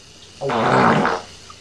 Wet Fart